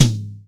TOM     2A.wav